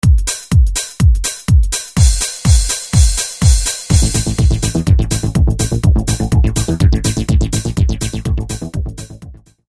Predov╣etk²m v s·Φasnej taneΦnej hudbe sa vyu╛φvaj· extrΘmne jednoduchΘ rytmy.
rytmus.